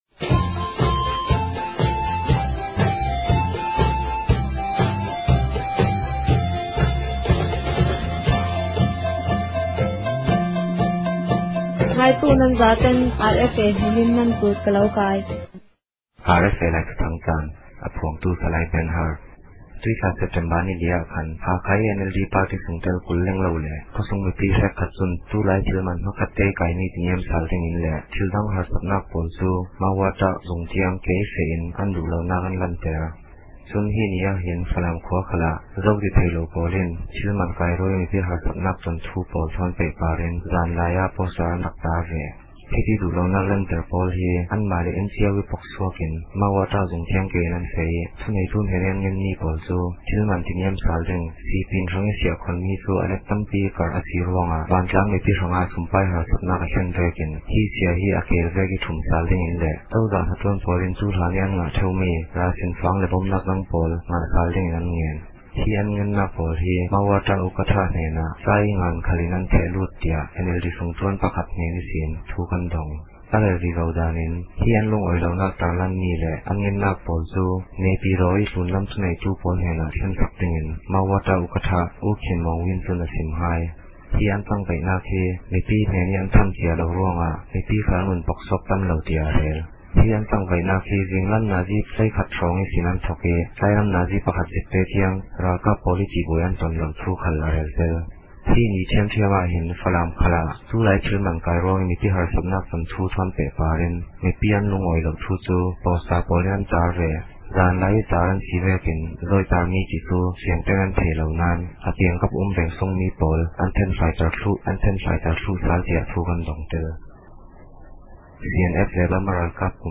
ခဵင်းဘာသာ အသံလြင့်အစီအစဉ်မဵား